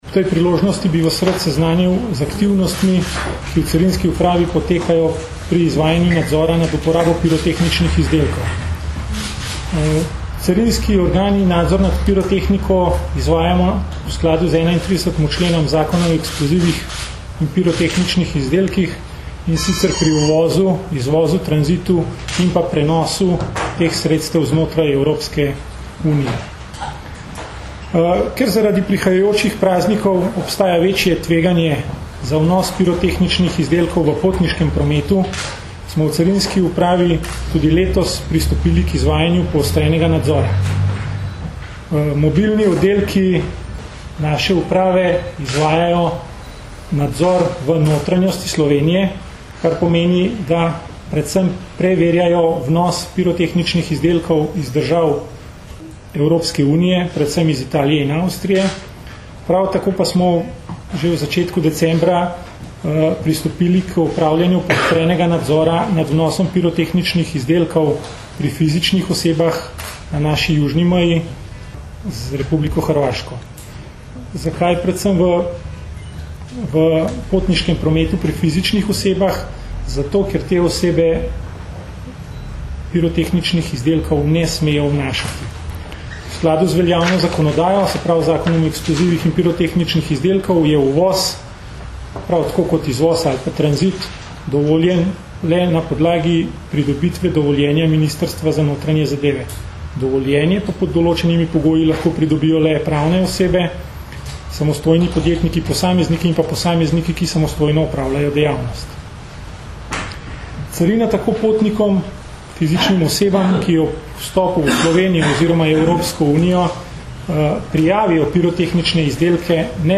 Na današnji novinarski konferenci smo zato predstavili naše aktivnosti in prizadevanja, da bi bilo med božično-novoletnimi prazniki čim manj kršitev in telesnih poškodb zaradi neprevidne, nepremišljene in objestne uporabe pirotehničnih izdelkov.